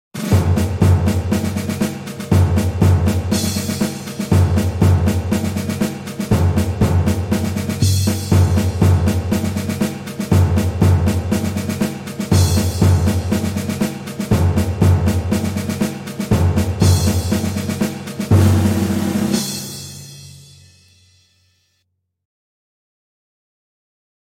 Free Sound effect Free Military sound effects free download
Free Sound effect Free Military March Drums